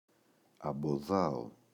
αμποδάω [aboꞋðao] – ΔΠΗ